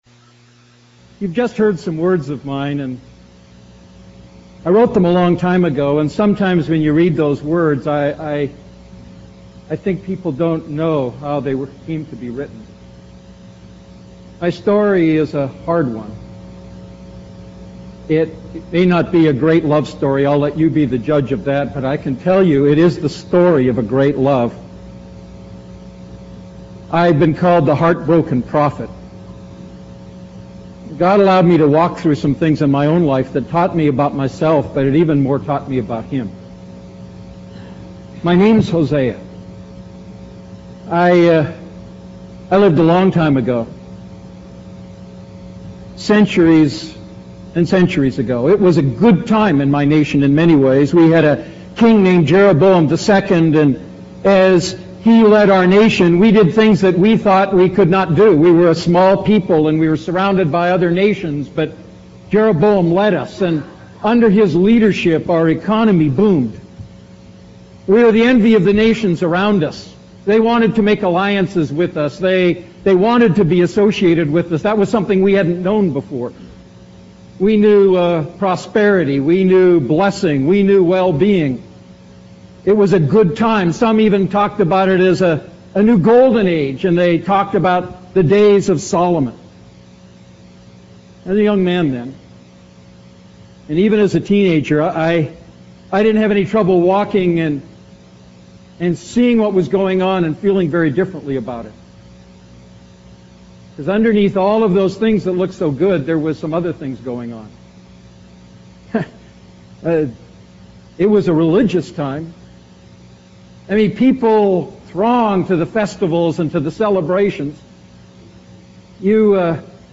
A message from the series "Defining Moments."